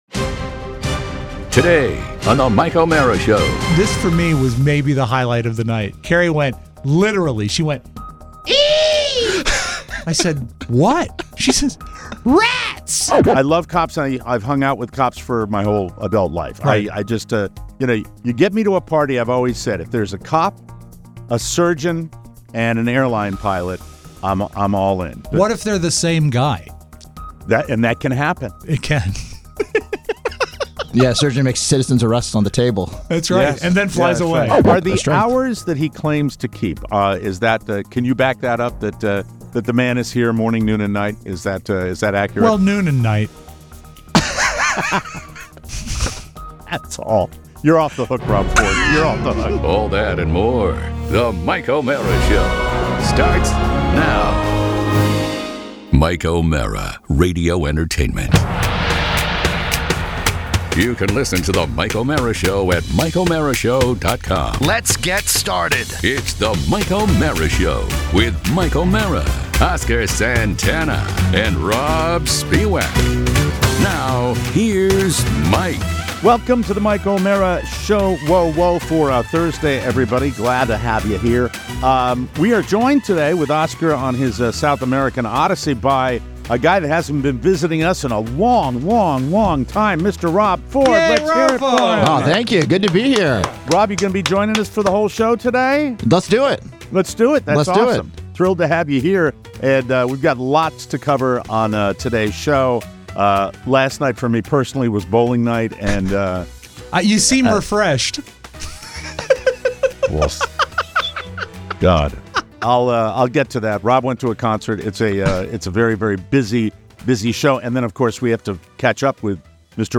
Today, we are joined in studio